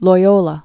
(loi-ōlə), Saint Ignatius of